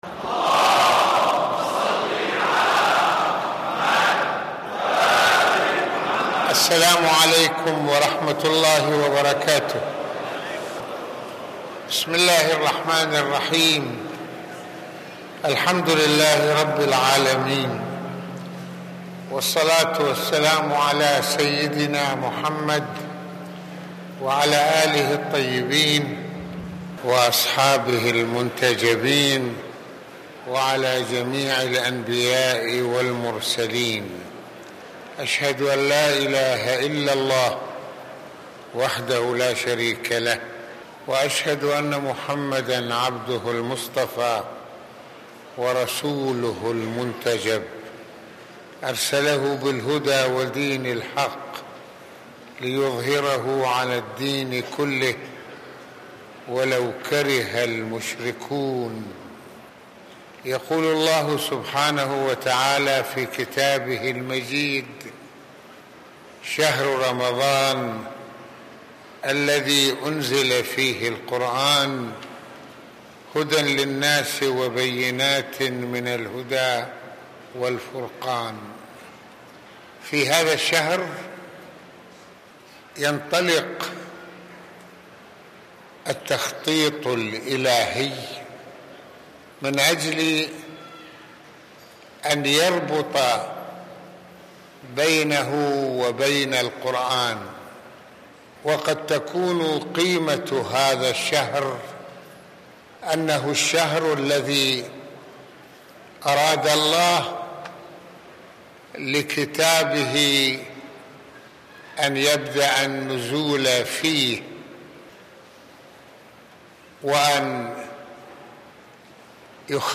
شهر رمضان موسم الثقافة القرآنية والإعداد الروحي | محاضرات رمضانية